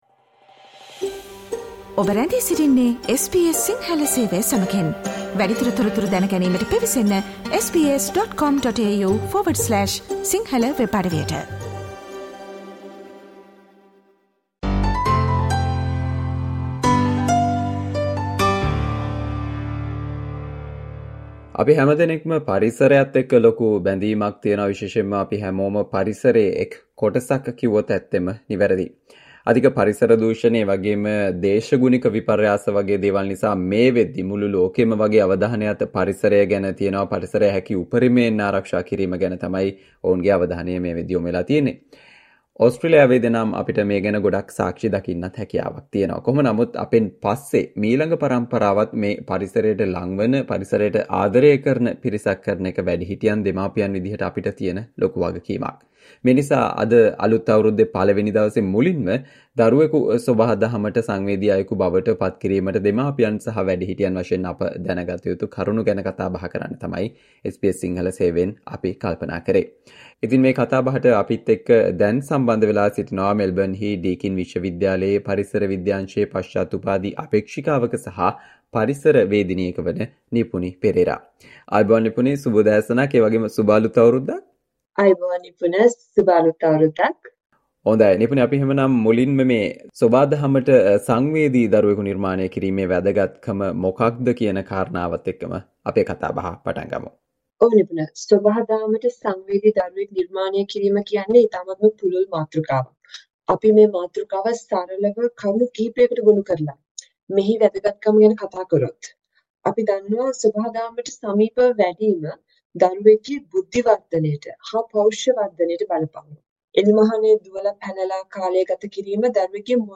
Listen to SBS Sinhala discussion on how to engage your child with the nature in Australia